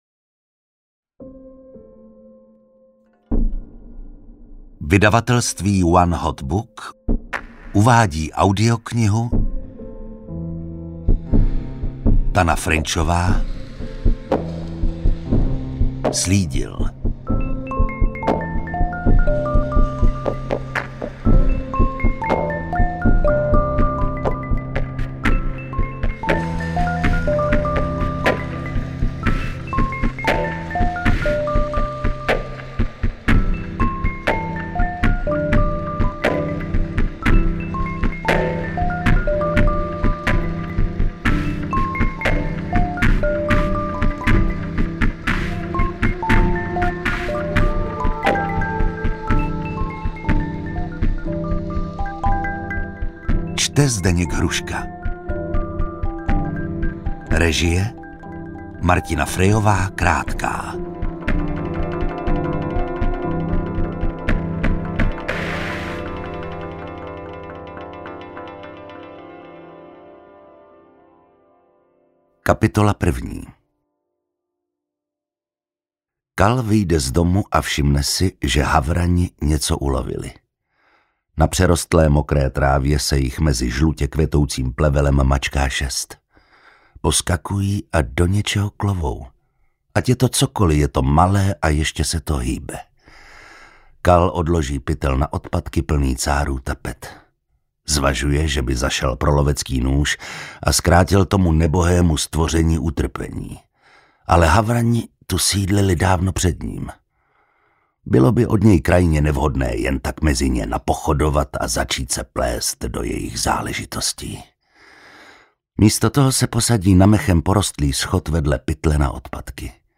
Interpret:  Zdeněk Hruška
AudioKniha ke stažení, 21 x mp3, délka 16 hod. 33 min., velikost 904,0 MB, česky